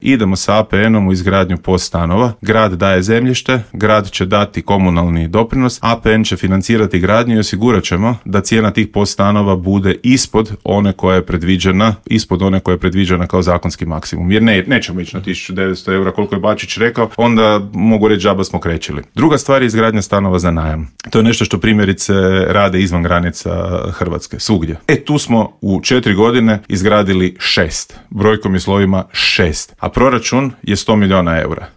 Borba se vodi i u Gradu Puli gdje poziciju čelnog čovjeka želi bivši predsjednik SDP-a i saborski zastupnik Peđa Grbin koji je u Intervjuu Media servisa poručio: